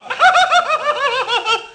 crazylaugh.wav